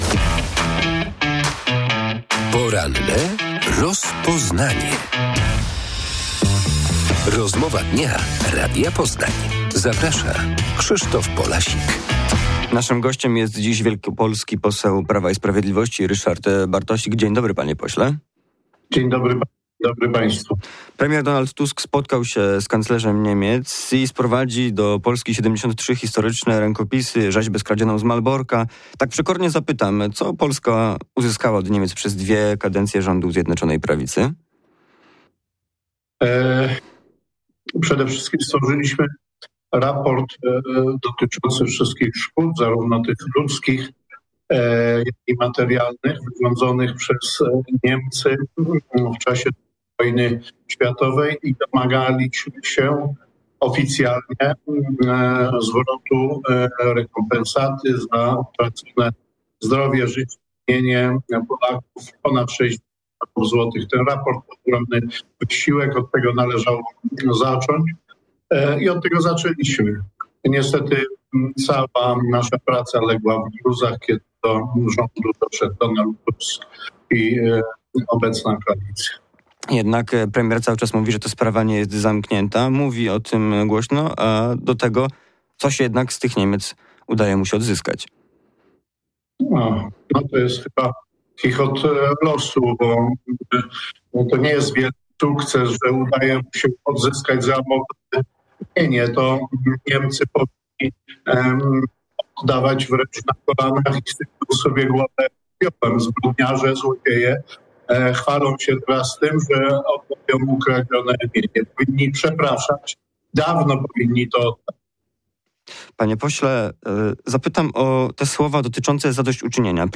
fep2nri90l0ec2t_rozmowa_bartosik_poranek.mp3